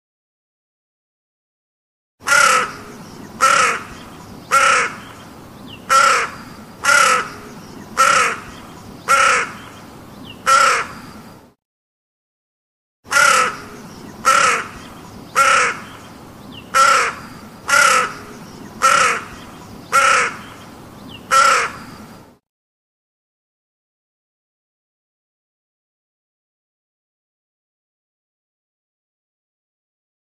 Category : Animals